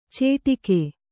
♪ cēṭike